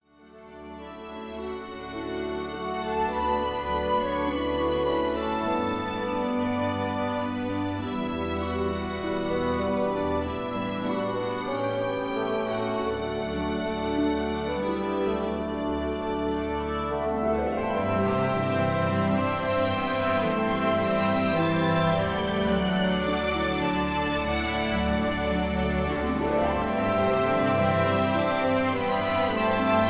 (key-D)
Just Plain & Simply "GREAT MUSIC" (No Lyrics).